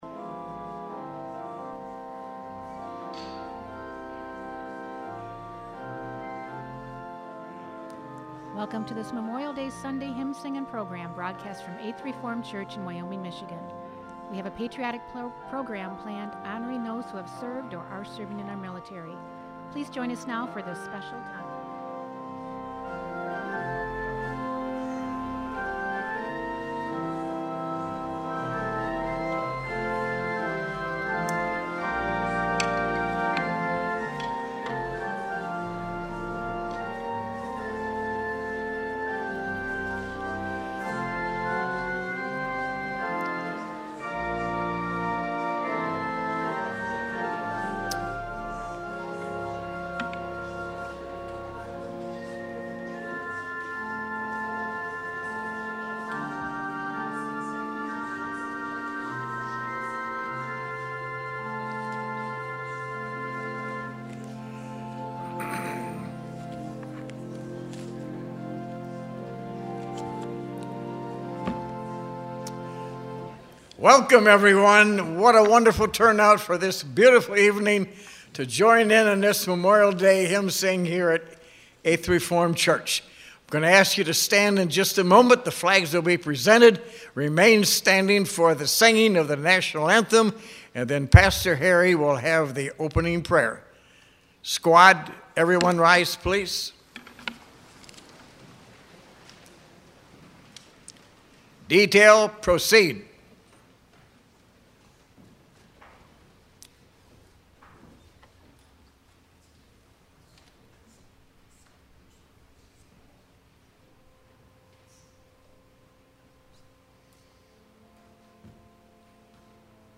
Memorial Day Patriotic Hymn Sing Program